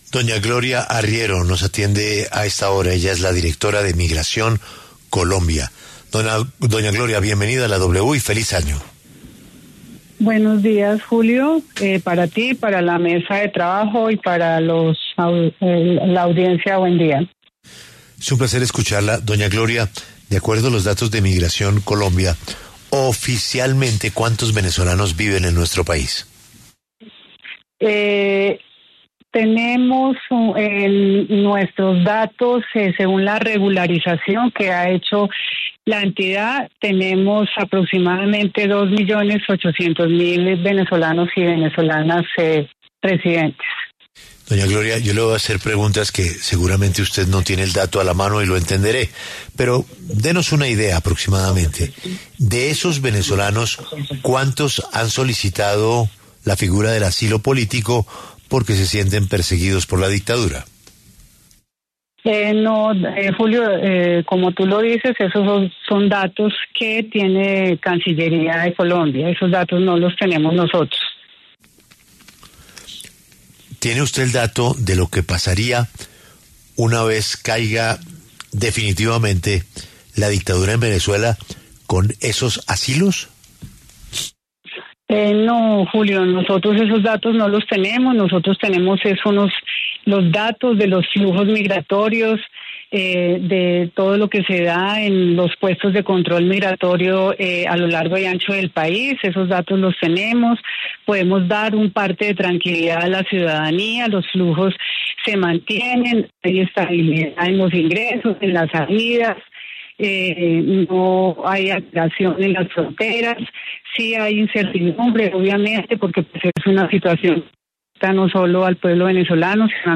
Gloria Arriero, directora de Migración Colombia, explicó en La W cómo se ha movido el flujo en las fronteras entre el país y Venezuela tras la captura de Nicolás Maduro.